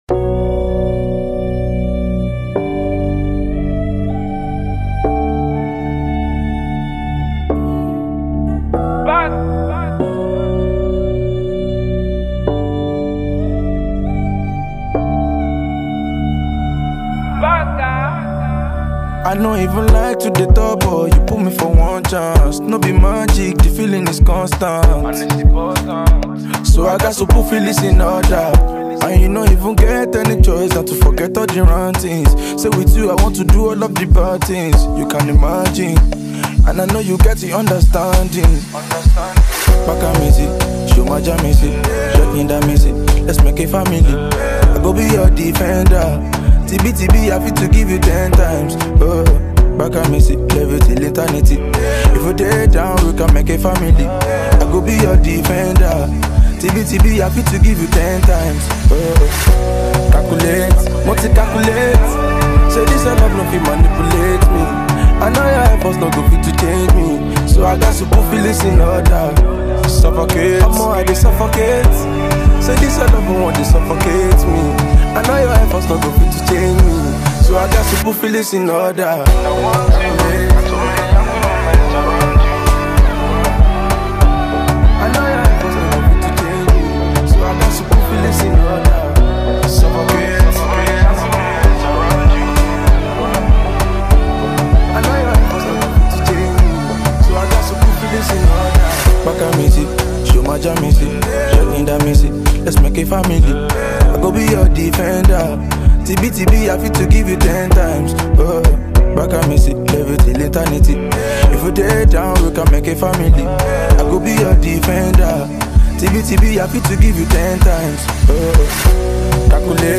Nigerian singer-songwriter